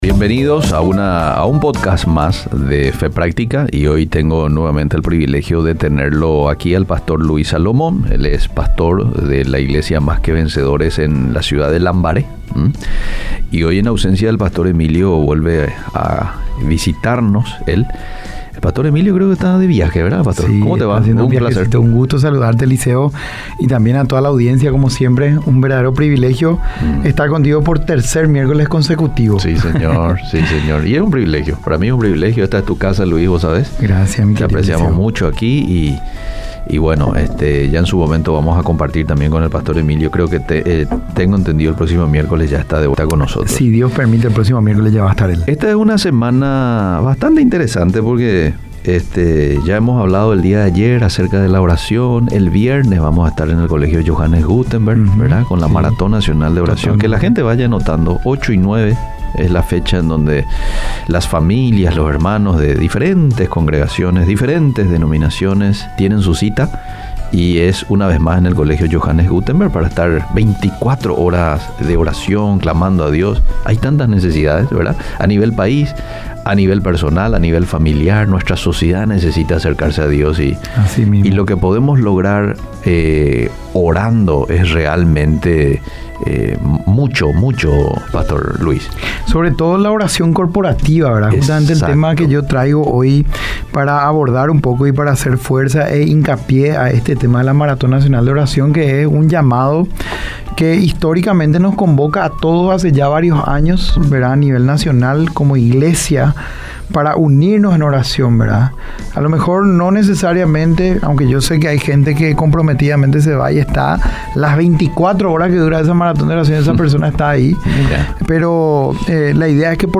Play Rate Listened List Bookmark Get this podcast via API From The Podcast Iglesia Más que Vencedores presenta: Un diálogo sobre aspectos que hacen a nuestro crecimiento, fundamentados sobre la fe.